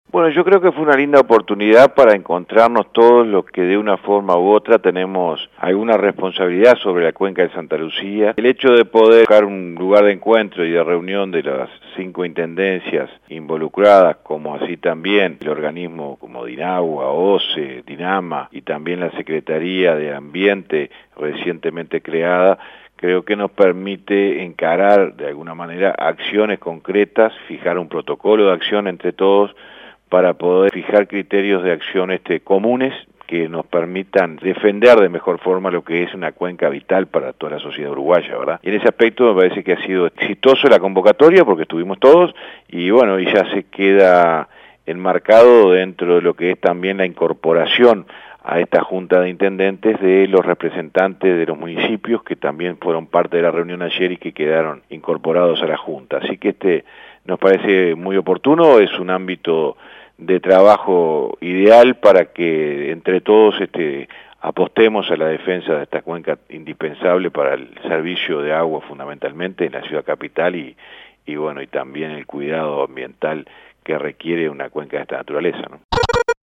El intendente de San José, José Luis Falero, dijo a Rompkbzas que fue una buena oportunidad para encontrarse los actores que tienen responsabilidad sobre la cuenca: Descargar Audio no soportado